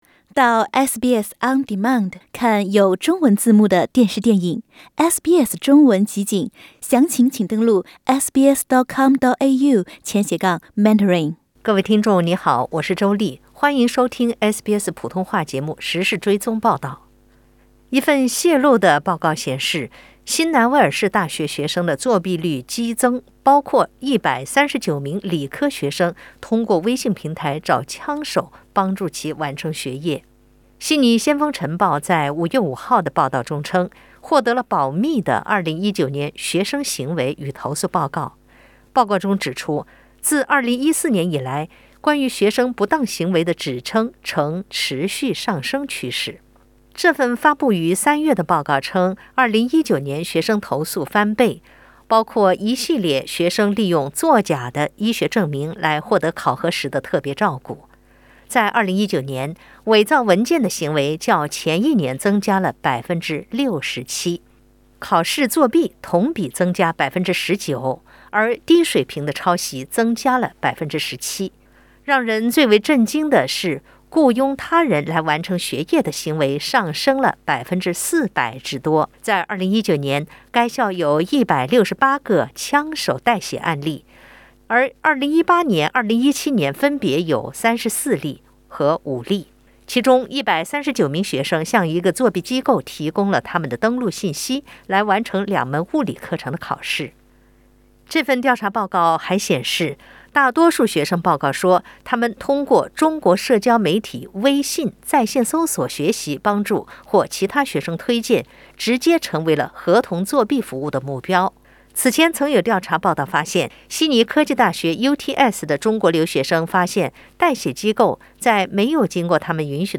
点击上图收听录音报道。